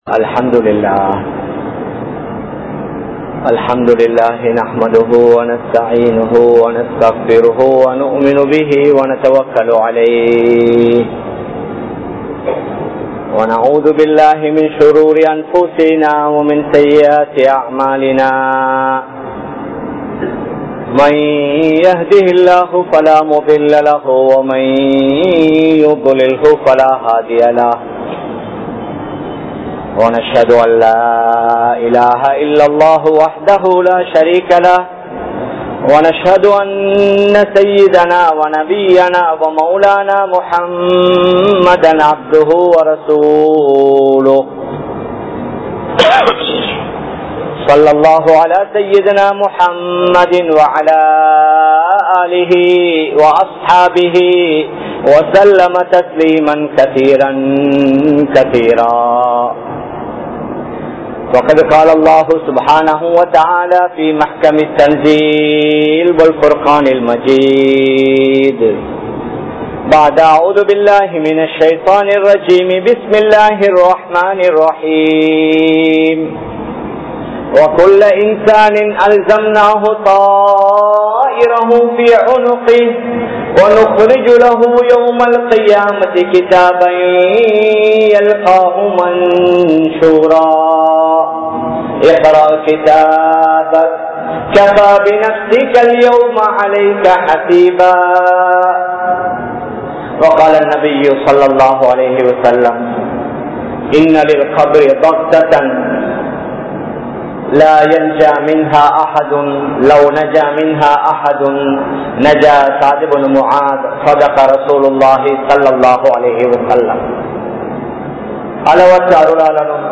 Qiyamath Naalin Adaiyaalangal [கியாமத் நாளின் அடையாளங்கள்] | Audio Bayans | All Ceylon Muslim Youth Community | Addalaichenai
Colombo 03, Kollupitty Jumua Masjith